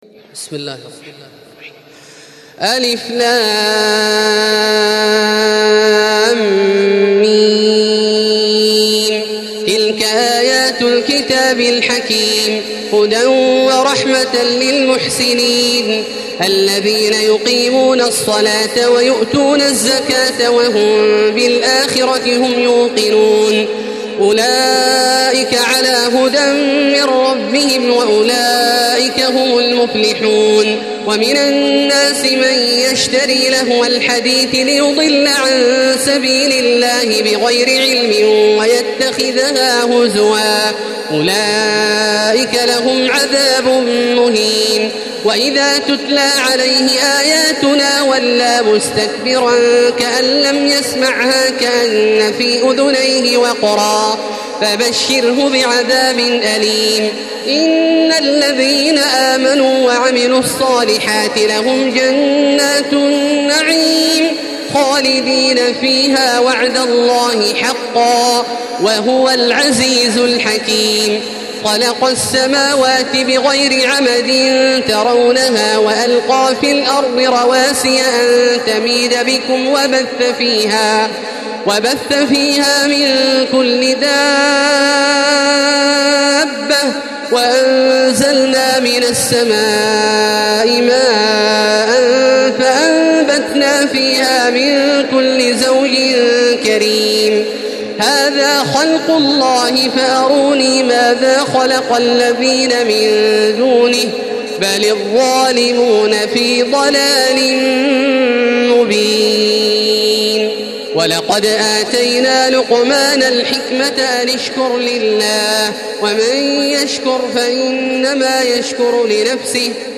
Surah লুক্বমান MP3 by Makkah Taraweeh 1435 in Hafs An Asim narration.
Murattal